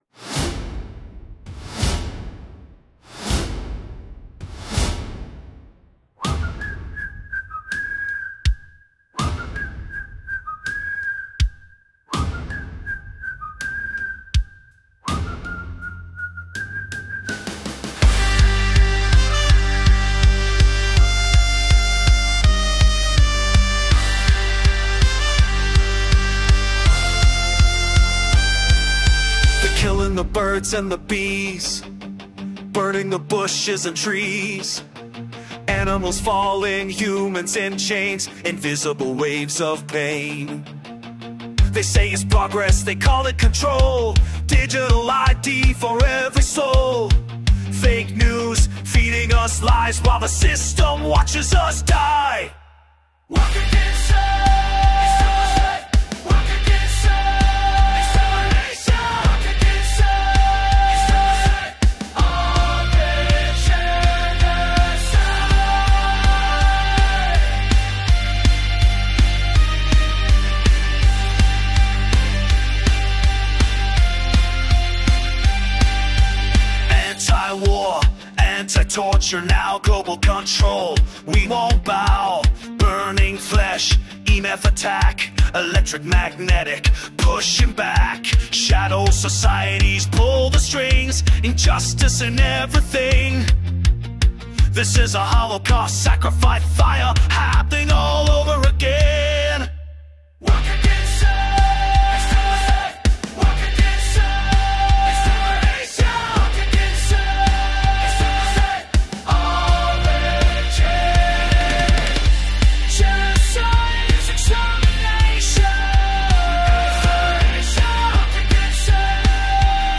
faster, chant heavy